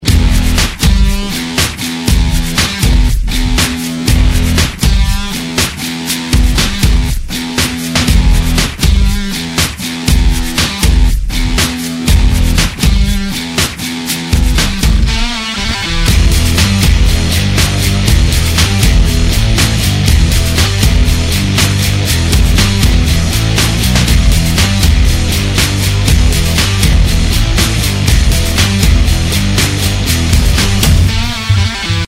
• Качество: 128, Stereo
без слов
инструментальные
саундтрек